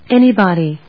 /énibὰdi(米国英語), énib`ɔdi(英国英語)/
フリガナエニーバディー